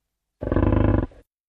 В этой подборке вы найдете рычание, фырканье, шаги и другие характерные звуки этих мощных животных.
звук яка